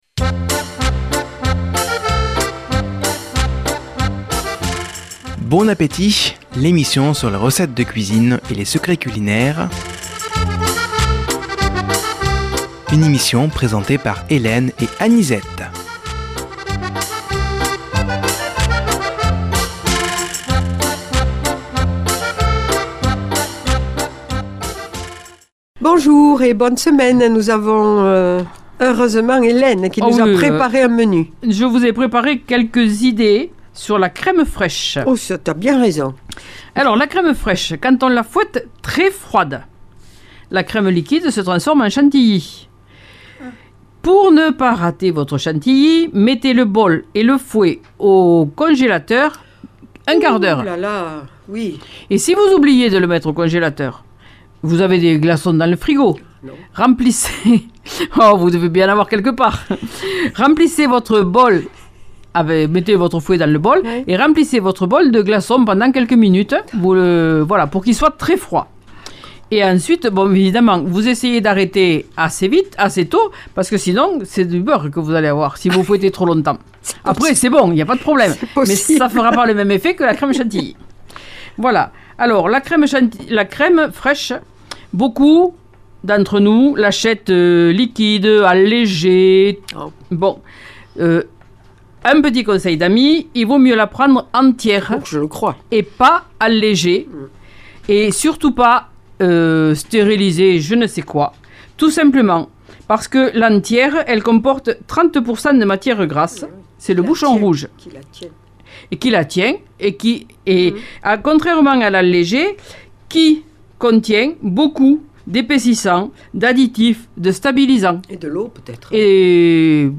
Une émission présentée par
Présentatrices